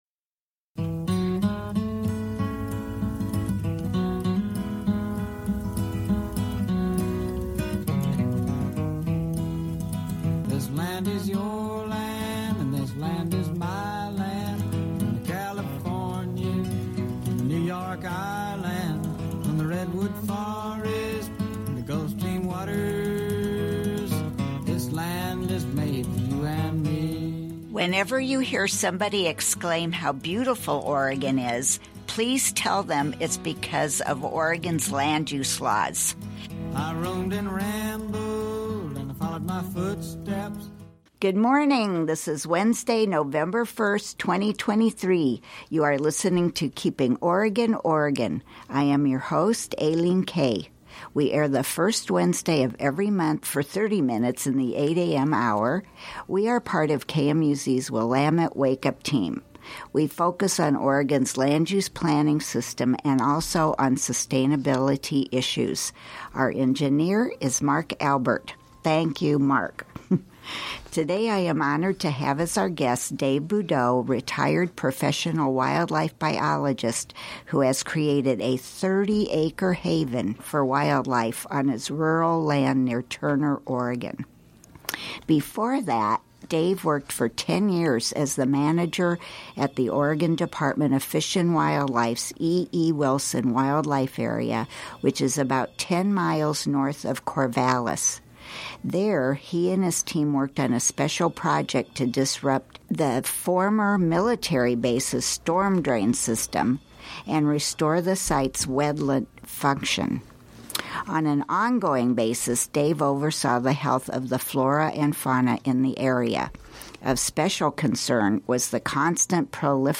KMUZ Willamette Wake Up interview